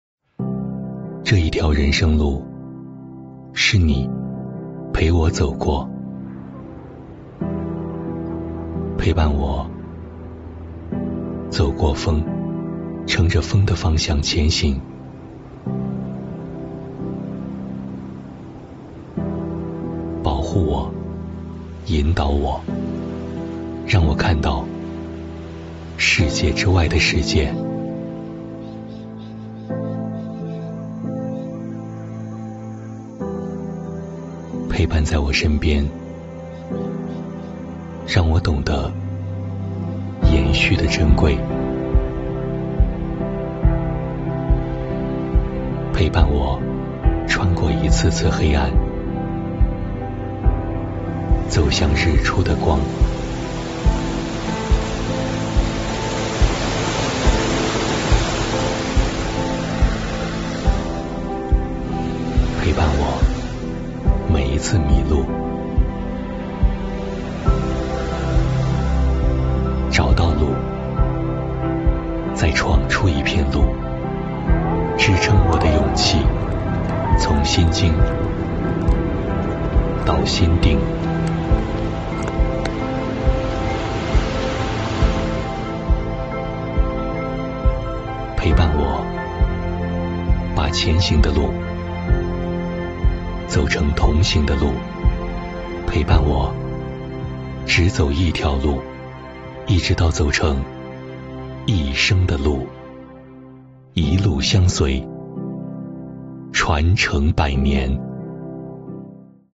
男国318_专题_银行_交通银行_温情.mp3